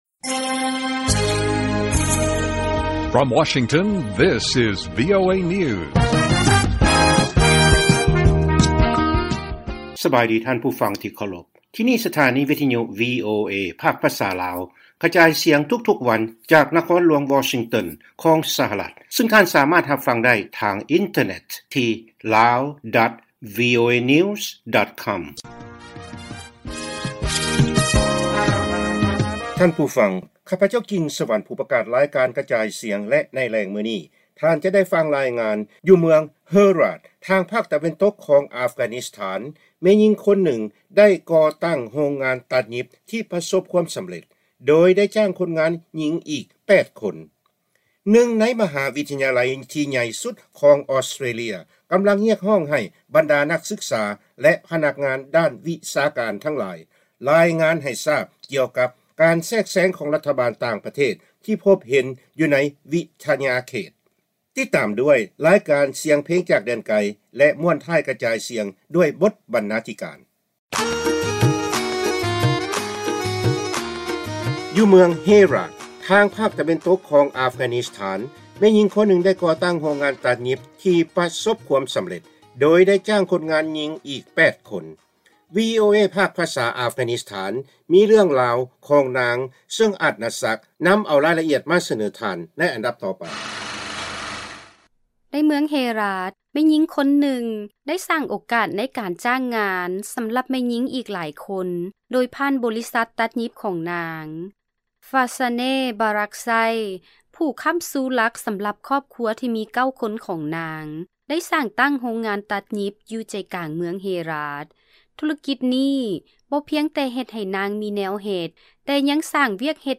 ລາຍການກະຈາຍສຽງຂອງວີໂອເອ ລາວ: ຊ່າງຕັດຫຍິບຊາວອັຟການິສຖານ ສ້າງແຮງບັນດານໃຈໃນການຈ້າງງານ ທ່າມກາງຄວາມທ້າທາຍຕ່າງໆ